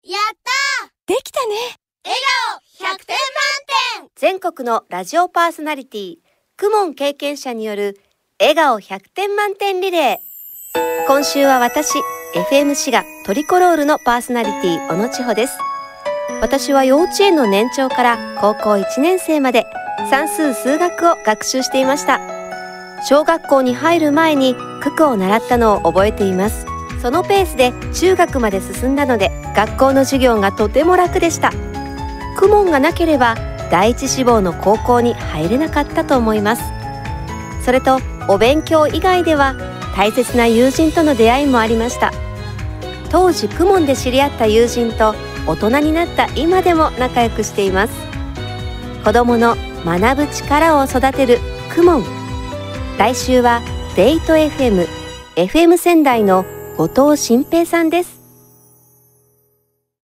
全国のパーソナリティの声